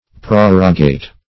Prorogate \Pro"ro*gate\, v. t.